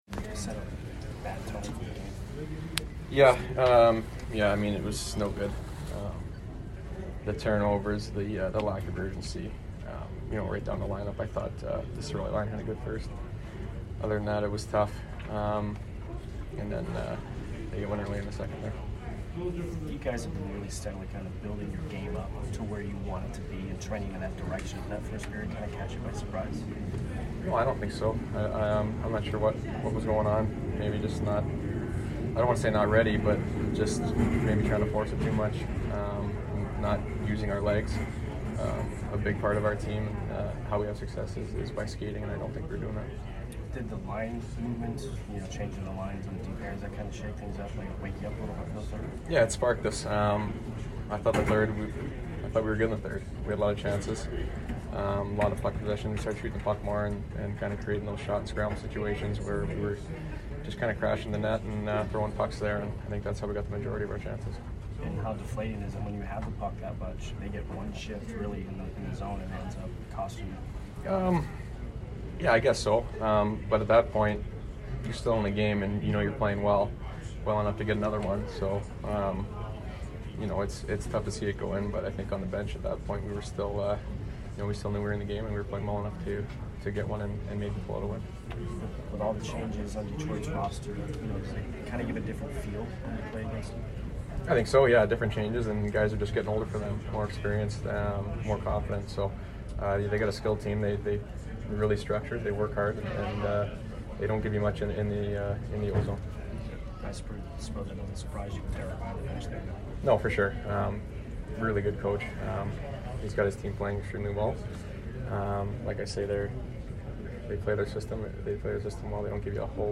Brayden Point Post Game 12/6/22 vs DET
Brayden Point Post Game 12/6/22 vs DET by Tampa Bay Lightning